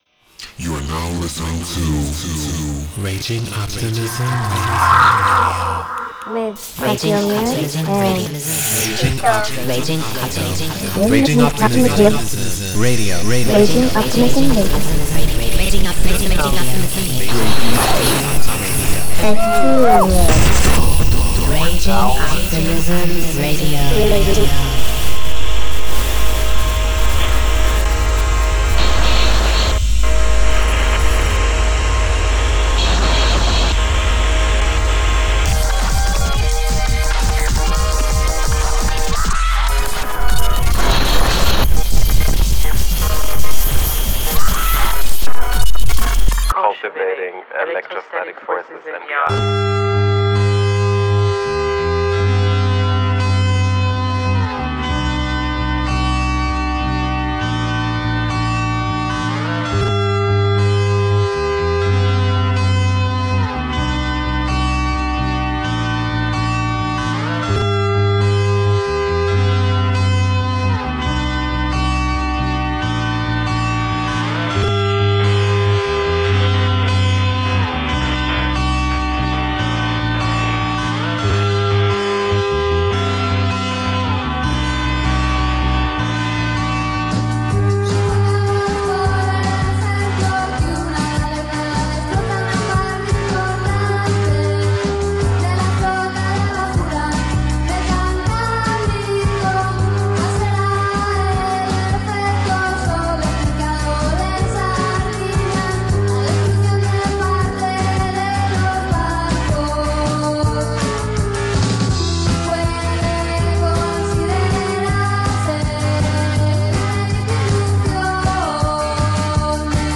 Alternative Electronic Experimental International Leftfield